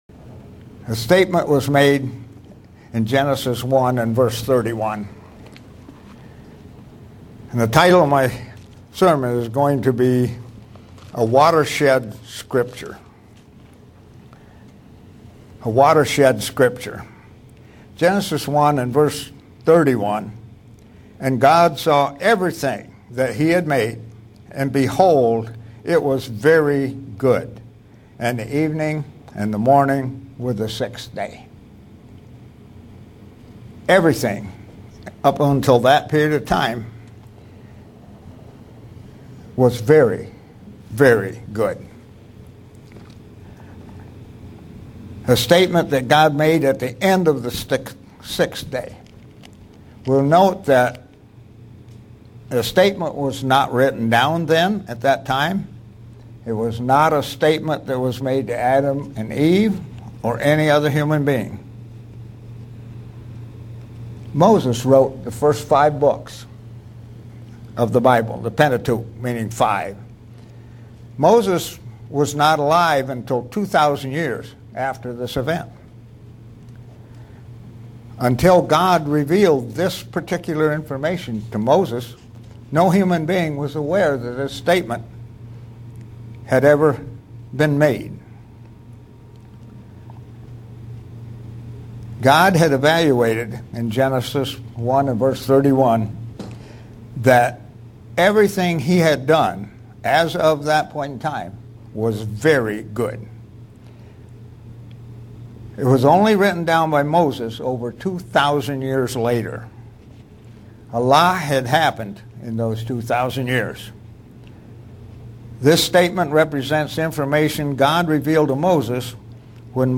Print To look at how this Scripture was perfectly put together by God. sermon Studying the bible?
Given in Buffalo, NY